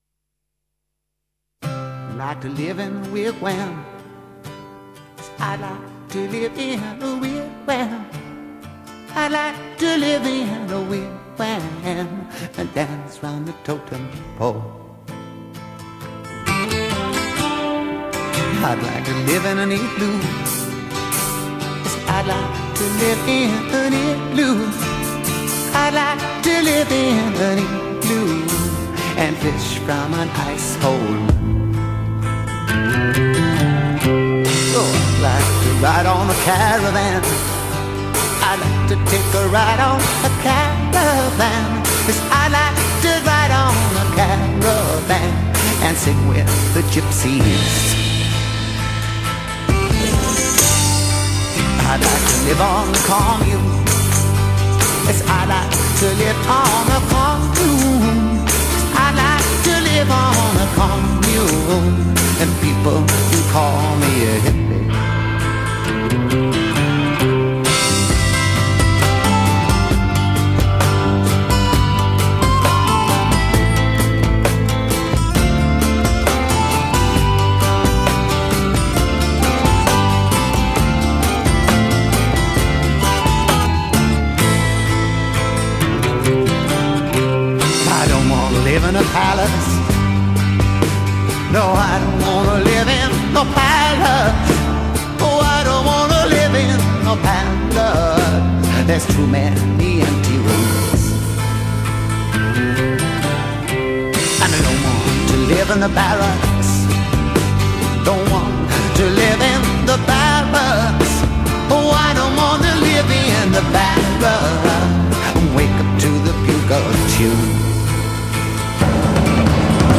It disappears – the sound of chanting begins: